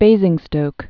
(bāzĭng-stōk)